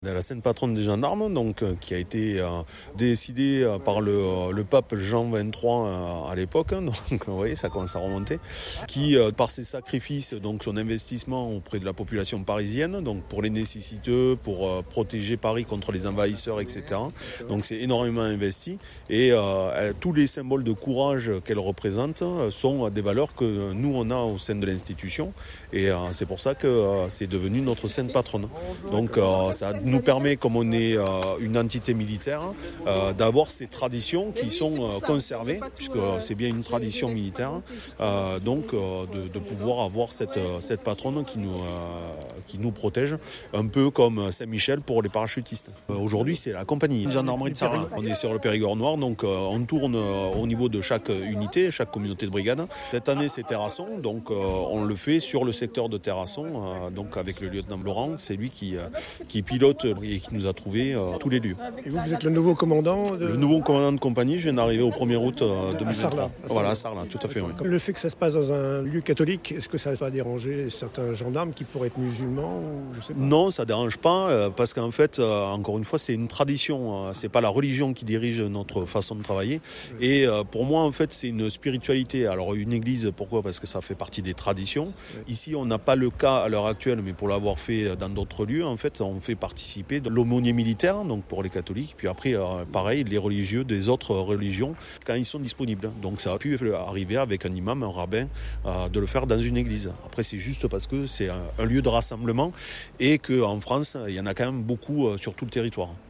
– Interview audio :